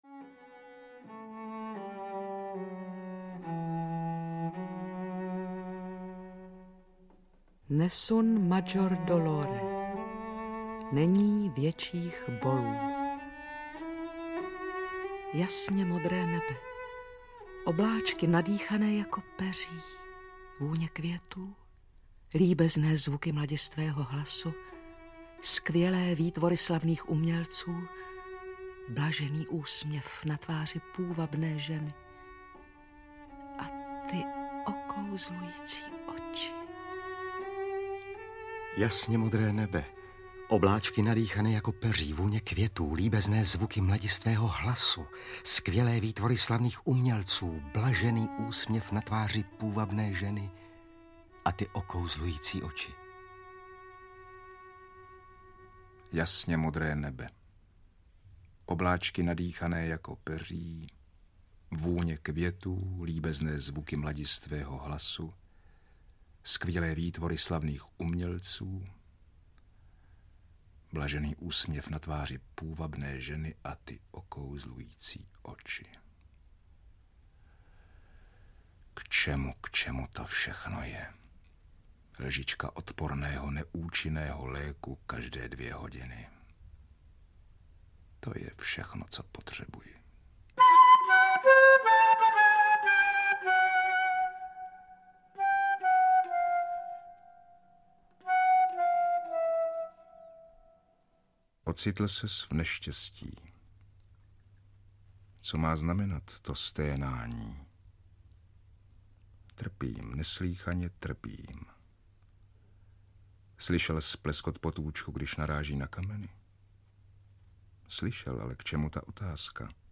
Poslední schůzka audiokniha
Ukázka z knihy